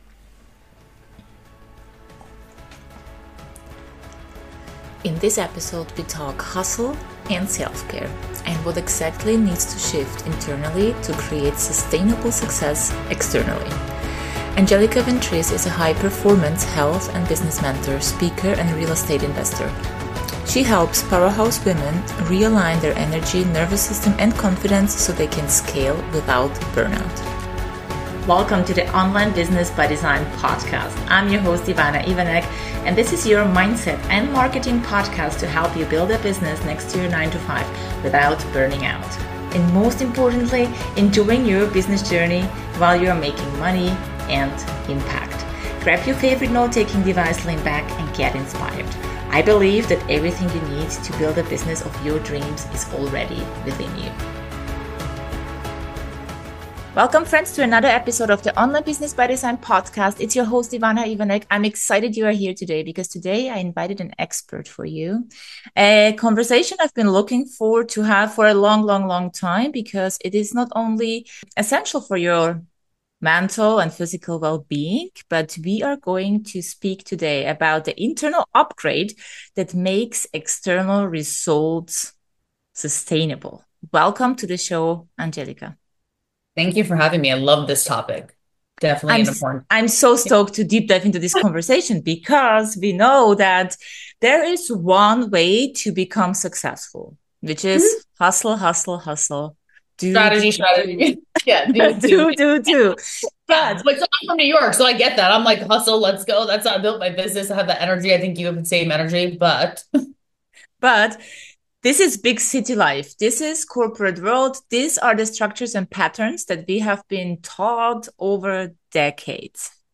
They dive into topics such as managing stress, maintaining mental and physical well-being, and the journey towards a balanced nervous system. The conversation highlights the phases of identity shifting—awareness, activation, embodiment, and mastery—and how each phase is vital for personal and professional growth.